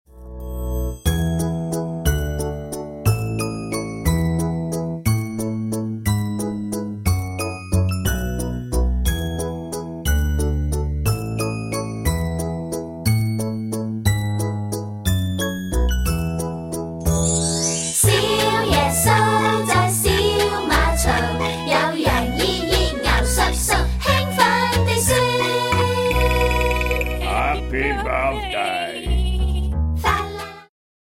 充滿動感和時代感
有伴奏音樂版本
伴奏音樂